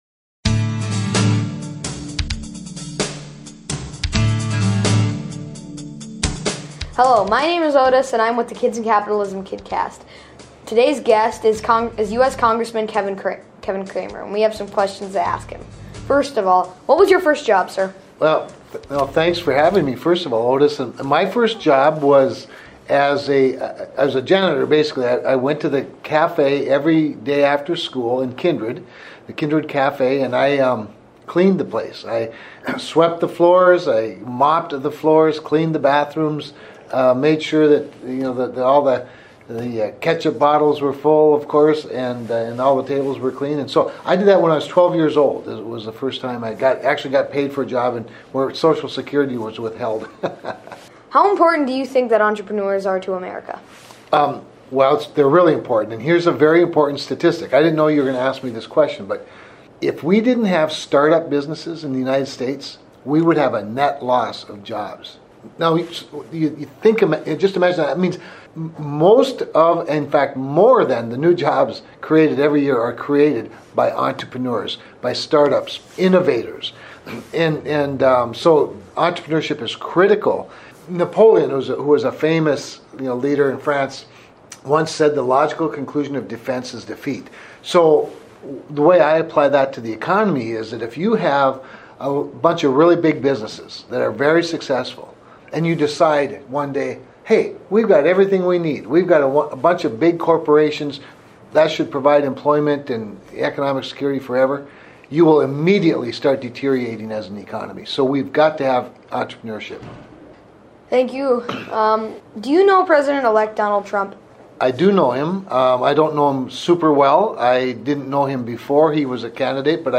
Interview: U.S. Congressman Kevin Cramer Talks about first jobs, how the government and business co-exist and what president elect Donald Trump is like.